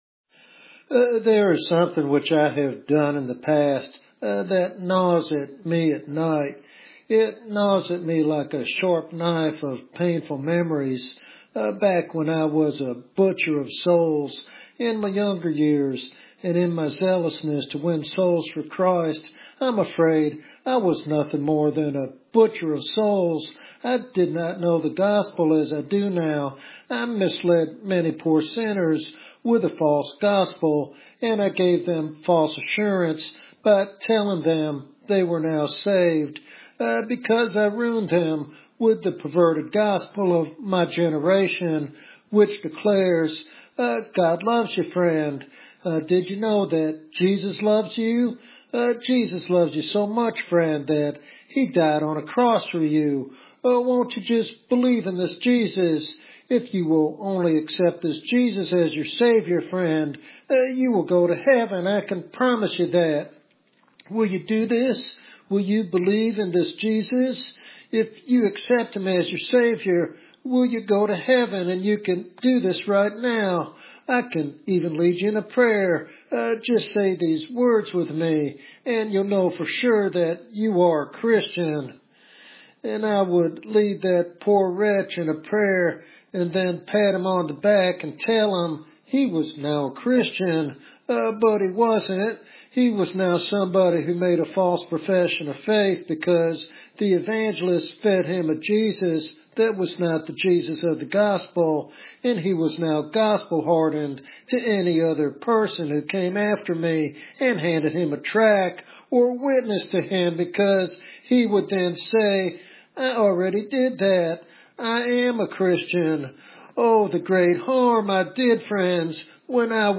This sermon challenges listeners to avoid superficial conversions and to rely on the Spirit's conviction for true transformation.